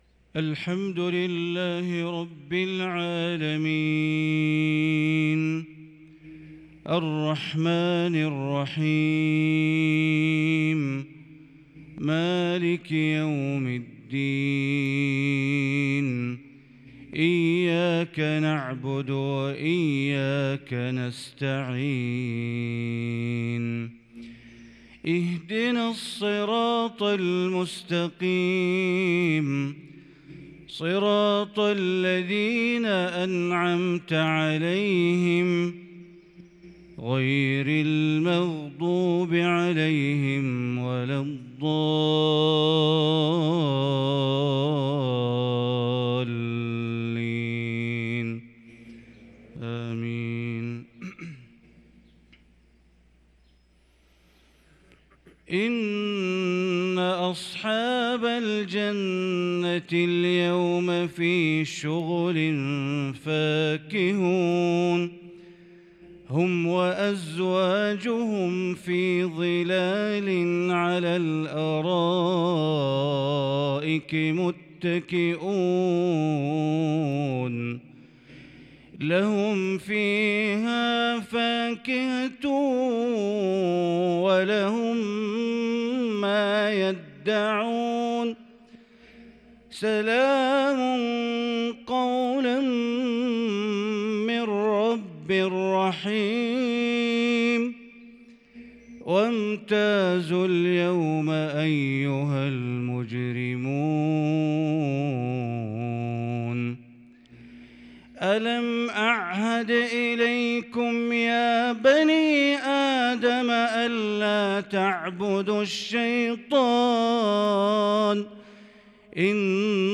صلاة الفجر للشيخ بندر بليلة 10 ربيع الأول 1444هـ